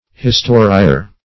Historier \His*to"ri*er\, n.